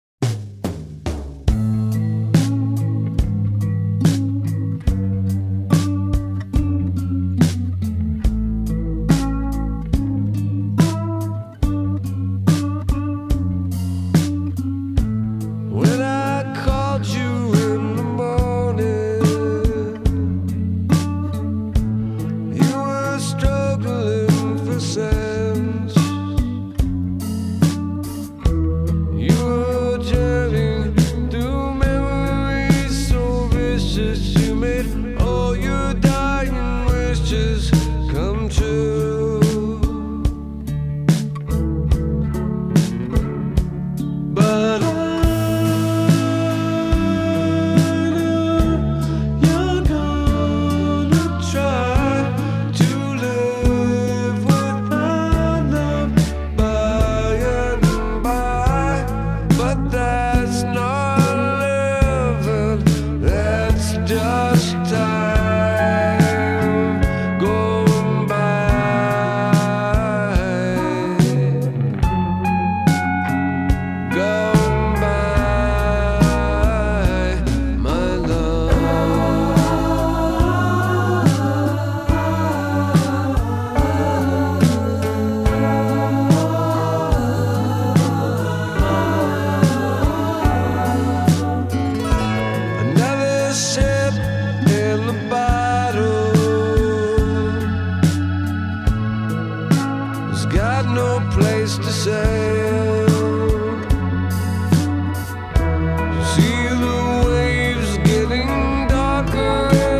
音質音效傑出！
全輯閃爍著心碎的弦音與濃郁的傷感